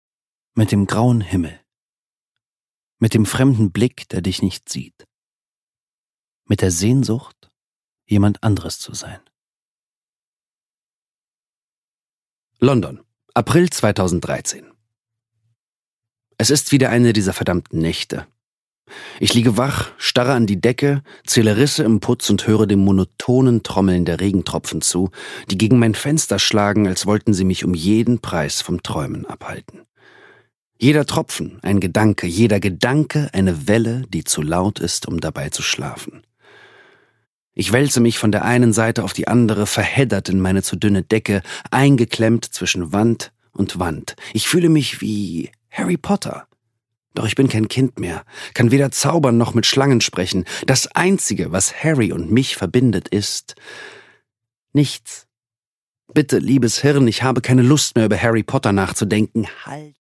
Daniel Donskoy: Brennen (Ungekürzte Lesung)
Produkttyp: Hörbuch-Download
Gelesen von: Daniel Donskoy